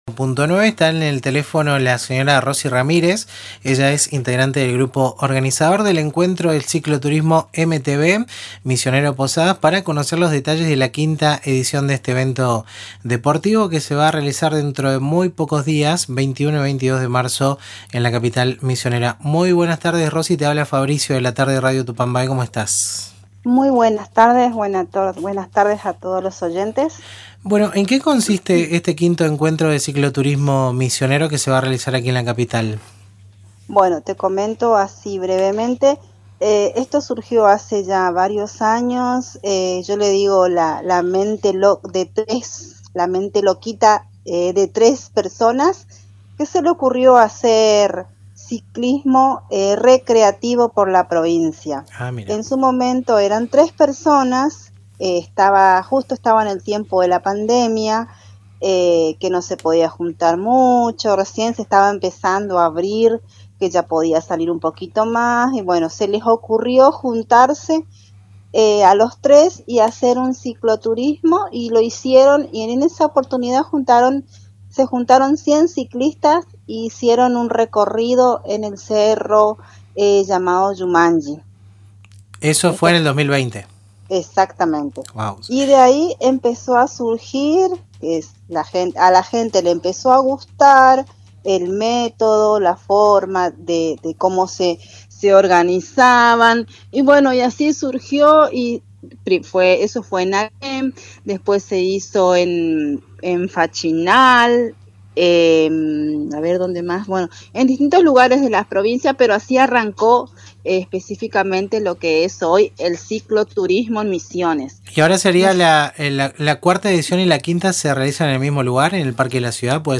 Escuchá la entrevista completa realizada en Radio Tupambaé: